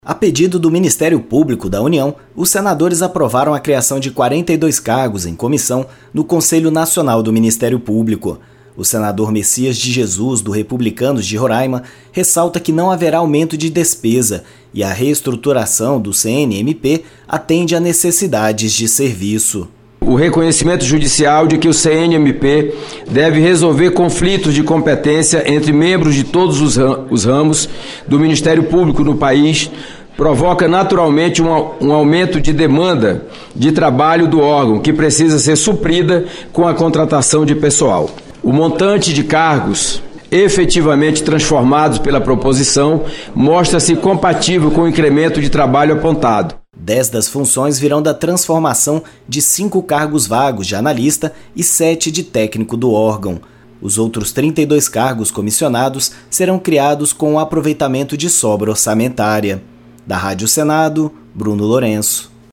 O Plenário do Senado aprovou mudanças na estrutura do Conselho Nacional do Ministério Público. A proposta (PL 2073/2022), que seguiu para a sanção presidencial, cria 42 cargos em comissão no órgão. O relator, senador Mecias de Jesus (Republicanos-RR), diz que não haverá aumento de despesas.